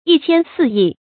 一谦四益 yī qiān sì yì
一谦四益发音